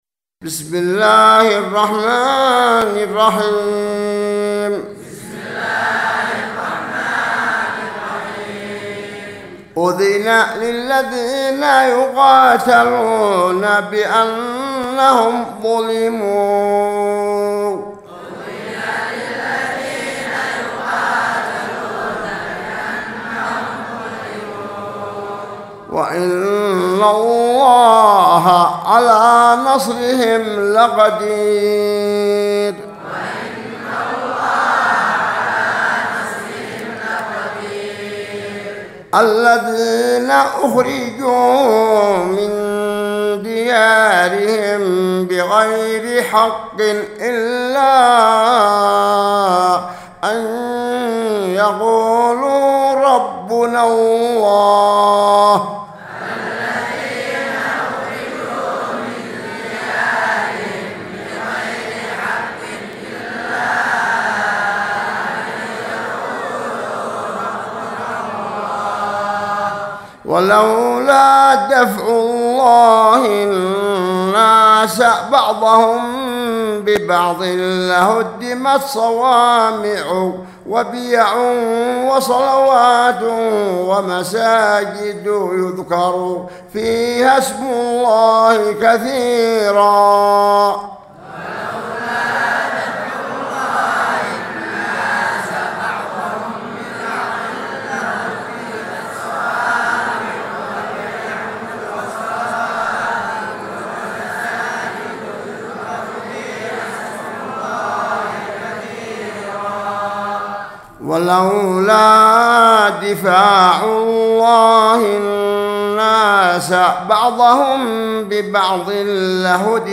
سورة الحج مع الترديد من 39 الى 46